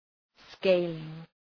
Προφορά
{‘skeılıŋ}